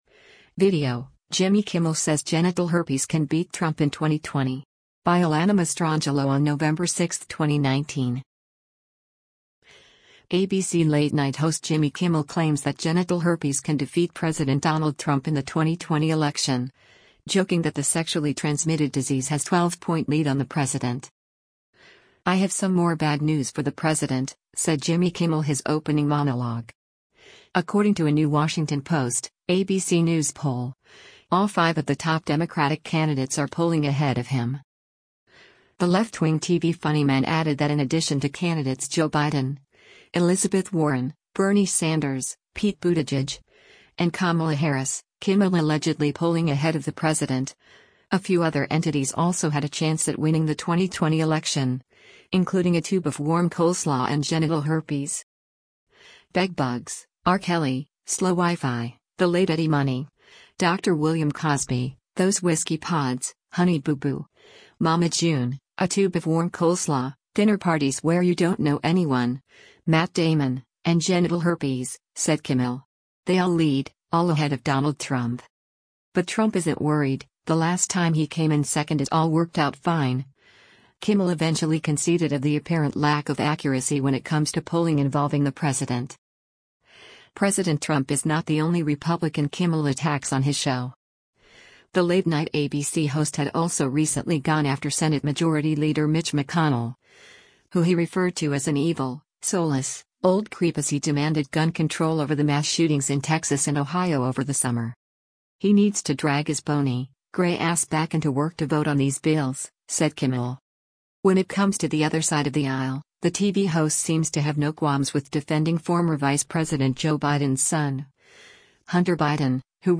“I have some more bad news for the president,” said Jimmy Kimmel his opening monologue.